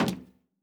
added stepping sounds
LowMetal_Mono_01.wav